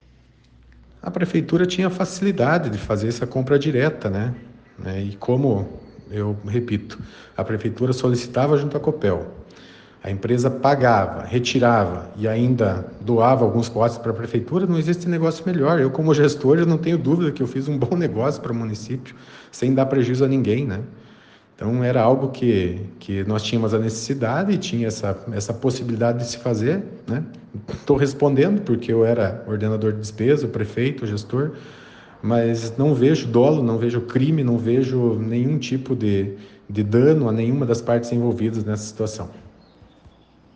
Ex-prefeito fala sobre a denúncia
E Edir Havrechaki falou sobre o assunto na edição desta quinta-feira, dia 13, do ‘Jornal da Cruzeiro’ (ouça nos áudios abaixo).